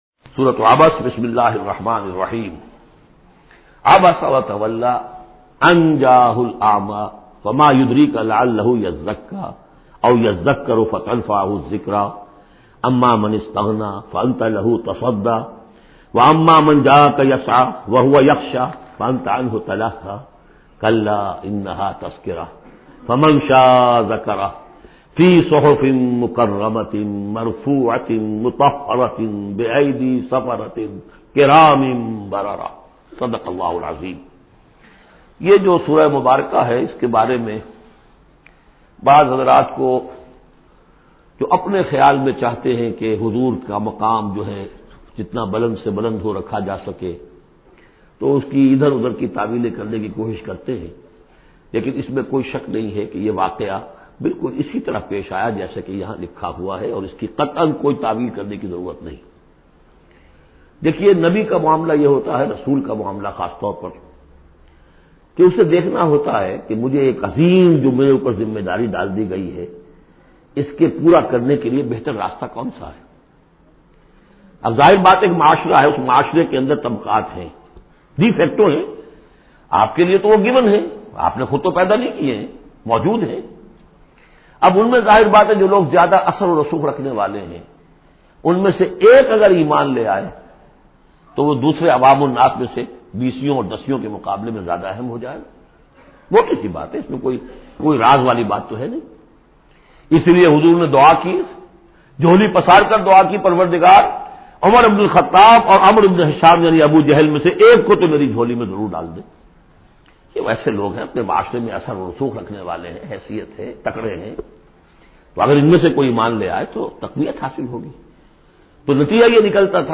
Surah Abasa Audio Tafseer by Dr Israr Ahmed
Surah Abasa is 80th chapter or Surah of Holy Quran. Listen online mp3 tafseer of Surah Abasa in the voice of Dr Israr Ahmed.